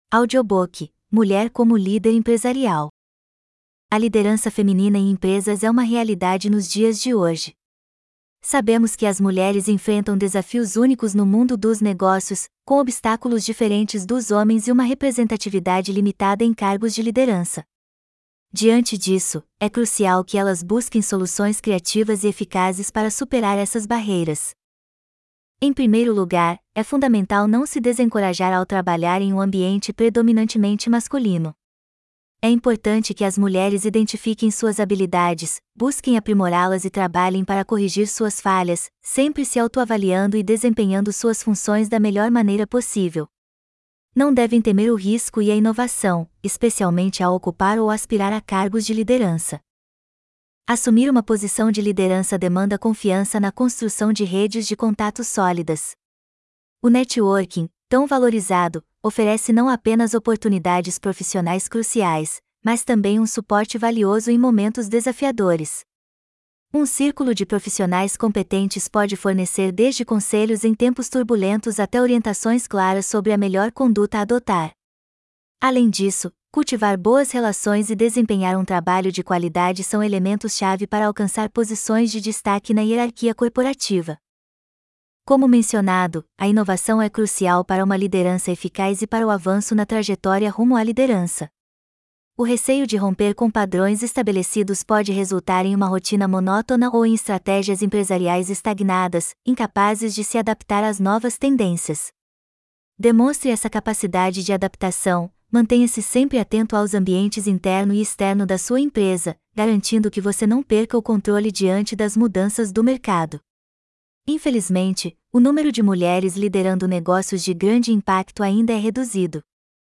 Audiobook mulher como líder empresarial - Sebrae
audiobook-mulher-como-líder-empresarial.mp3